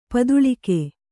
♪ paduḷike